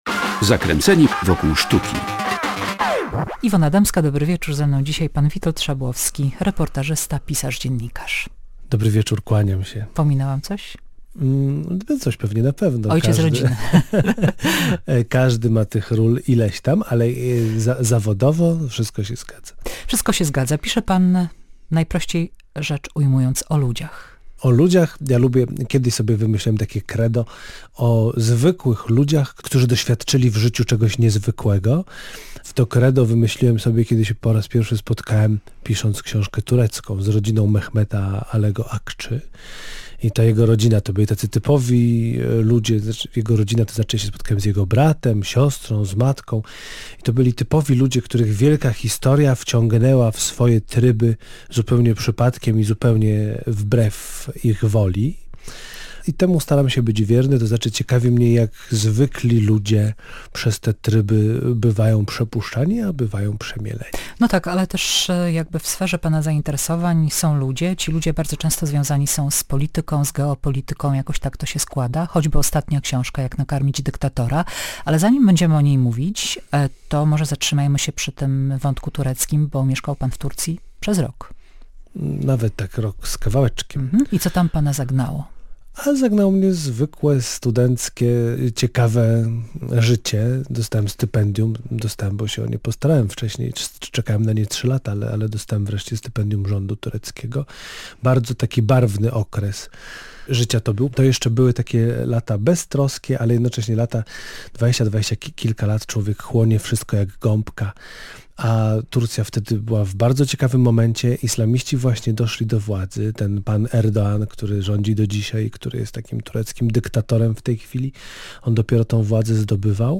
Rozmowa z dziennikarzem i reportażystą, autorem książki „Jak nakarmić dyktatora”- Witoldem Szabłowskim